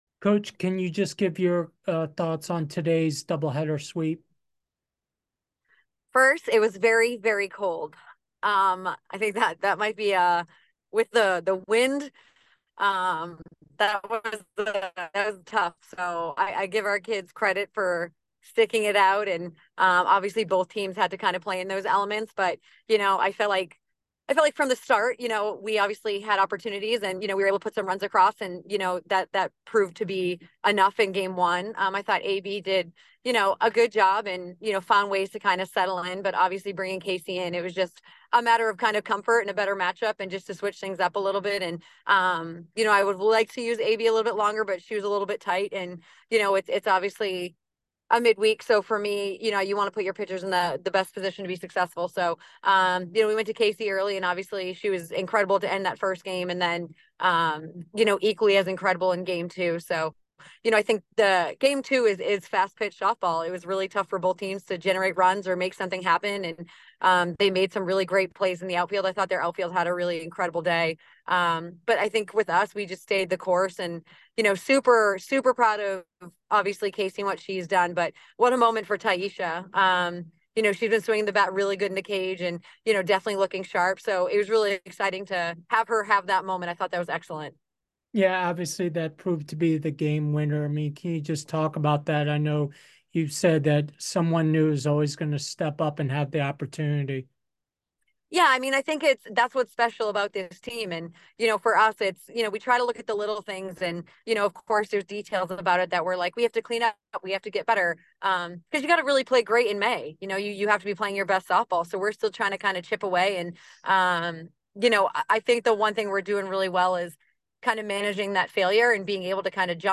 UConn DH Postgame Interview